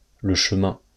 [lə ʃəmɛ̃](info) ìsch a frànzeescha G’mainda mìt 59 Iiwoohner (Schtànd 1.